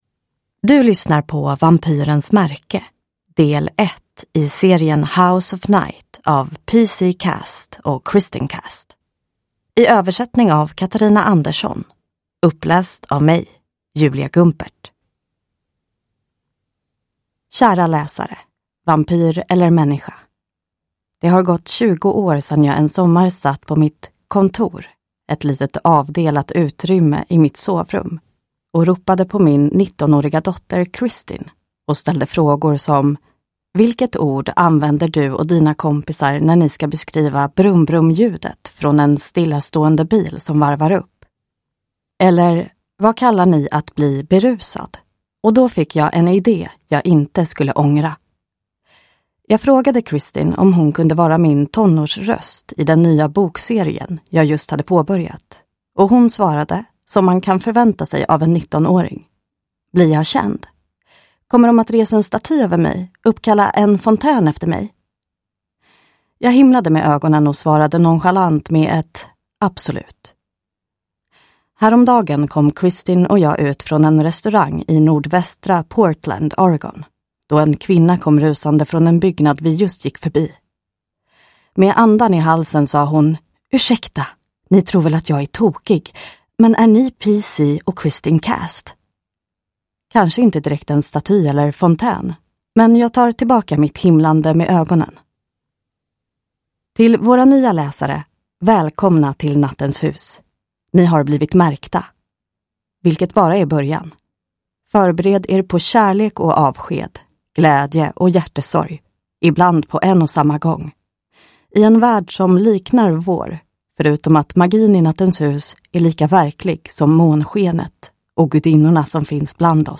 Vampyrens märke – Ljudbok